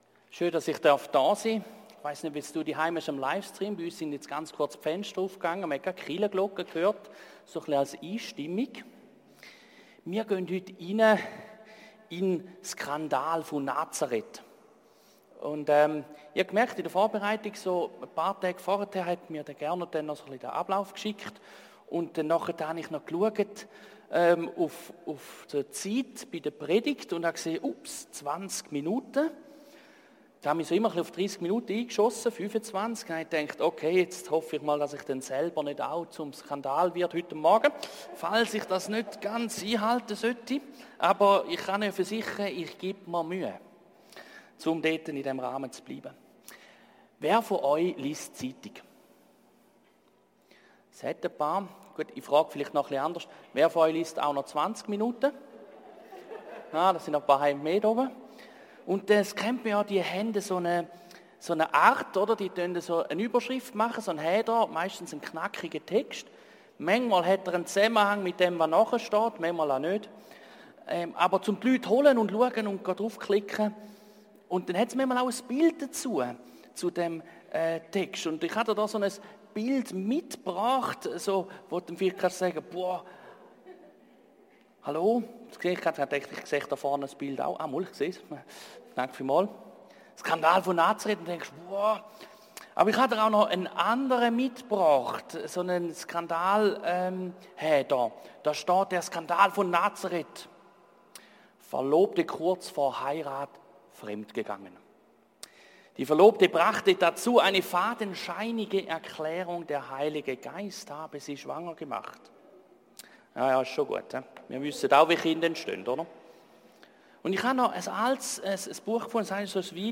REALationships – Predigtserie